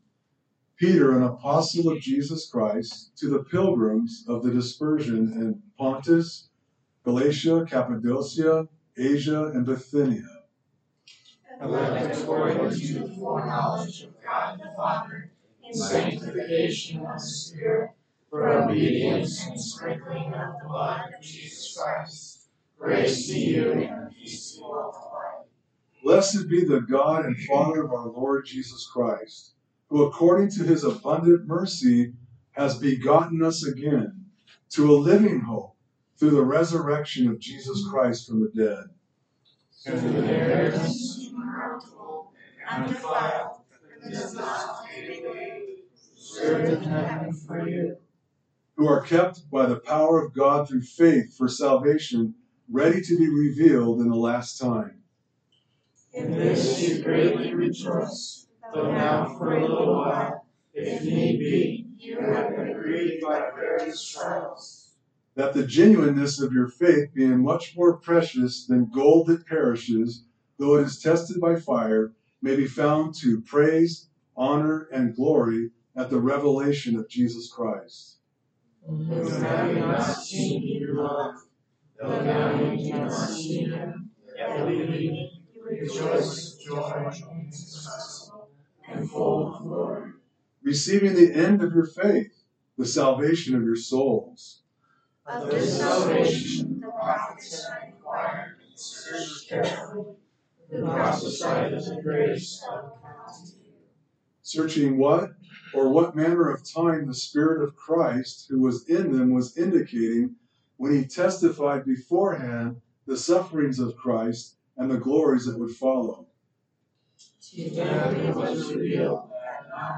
A message from the series "1 Peter."